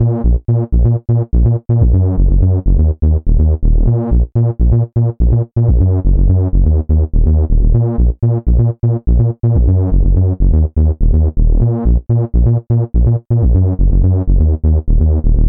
欢乐屋基斯林合成回路
描述：爵士乐的低音循环
Tag: 124 bpm House Loops Synth Loops 2.60 MB wav Key : Unknown